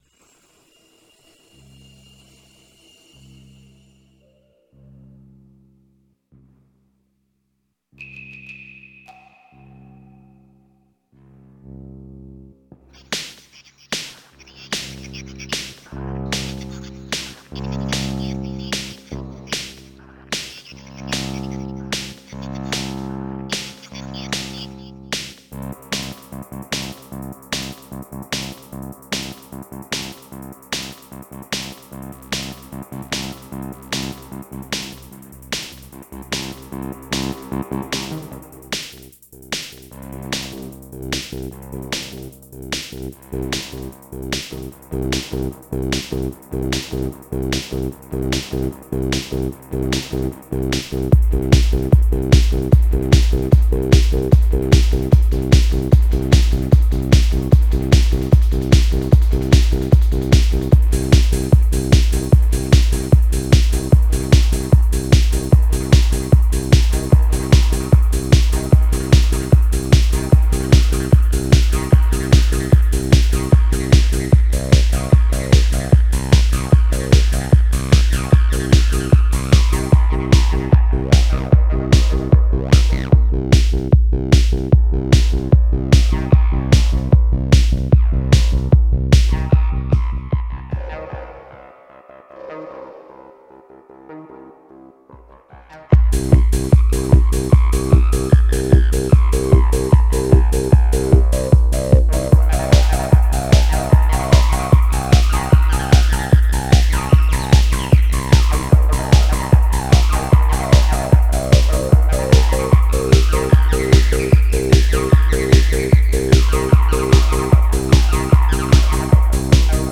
Viel Acid und viel Rhythmus – das war schon prägend!